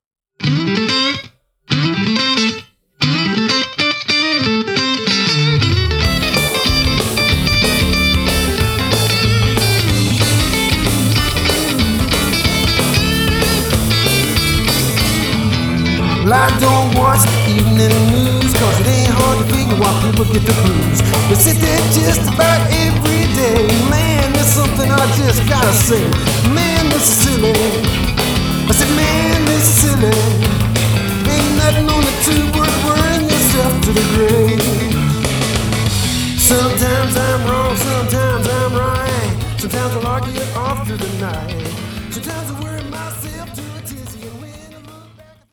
Website background music